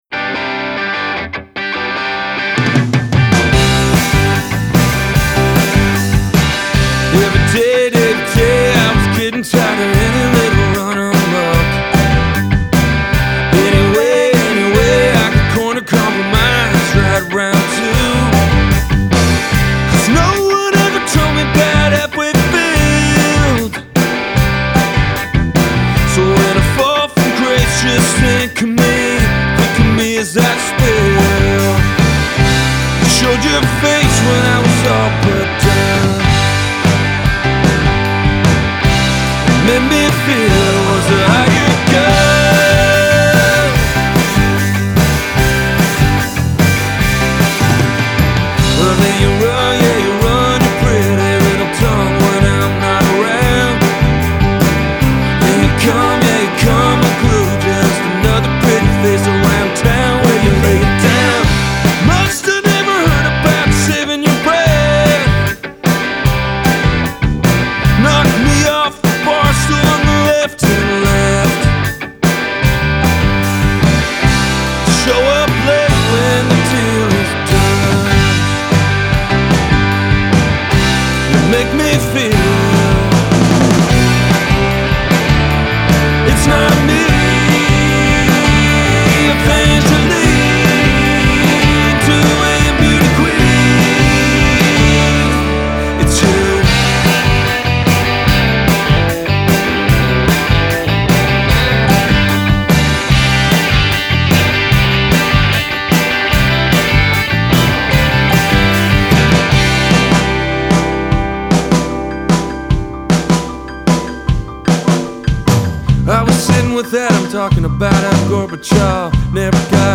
The vibe is richer and swinging again
worn, earthy vocals, bar-room honky piano